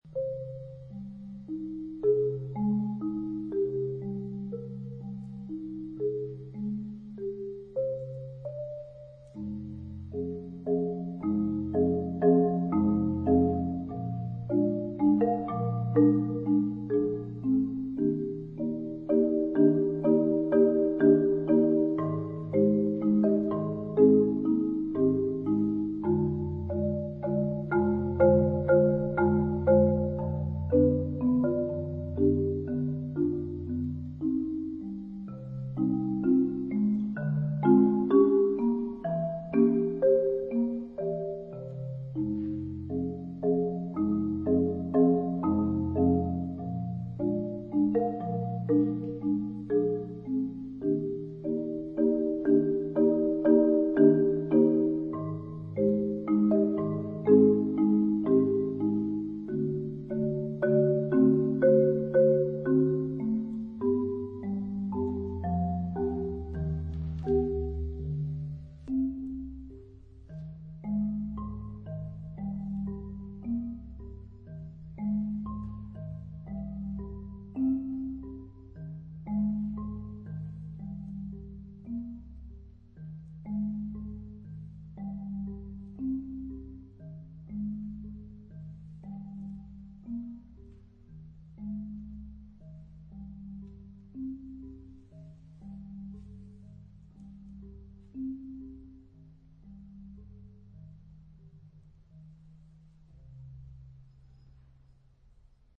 試聽二是打擊樂的冥想曲，馬林巴琴創造的想像空間，也挺厲害的。